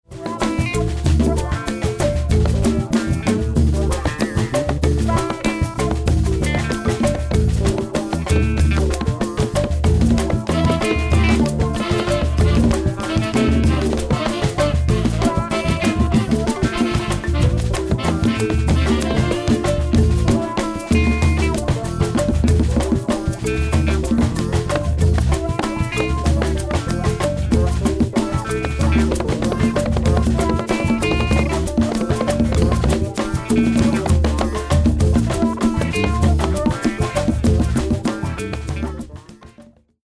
Mixing Monster Folk Sample
Before / Raw Tracks